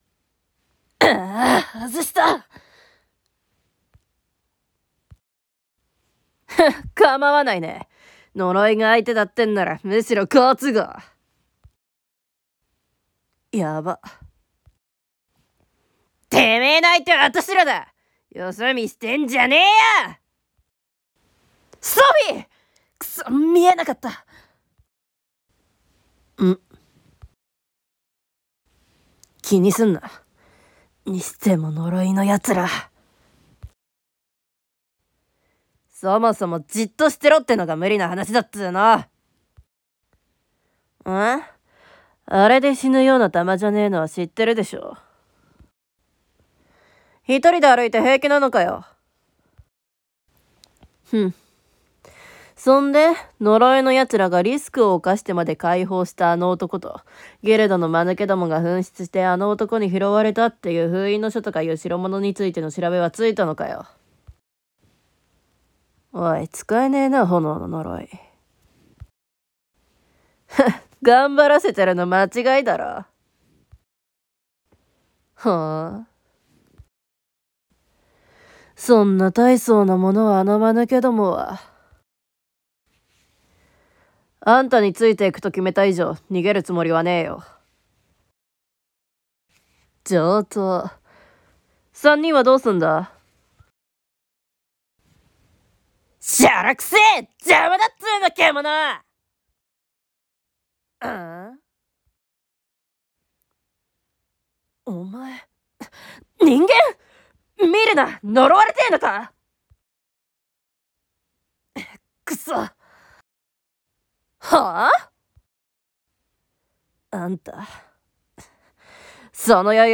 呪い killer 声劇